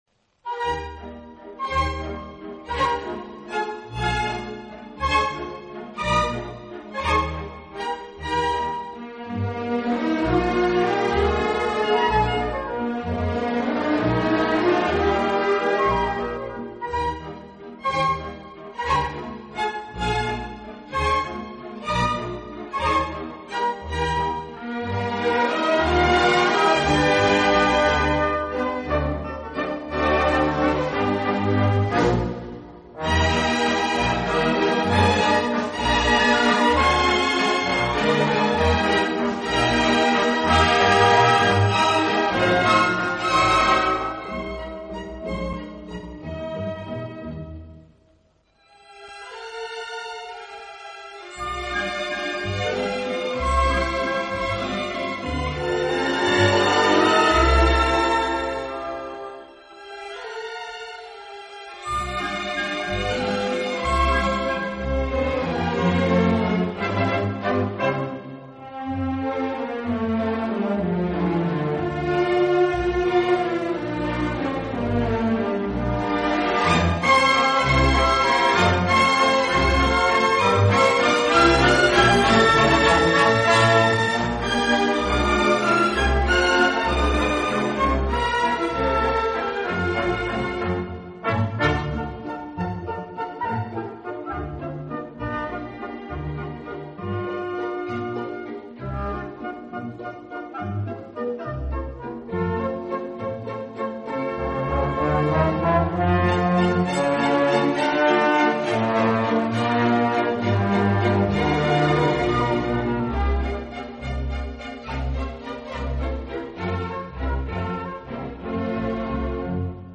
Genre: Instrumental
舒展，旋律优美、动听，音响华丽丰满。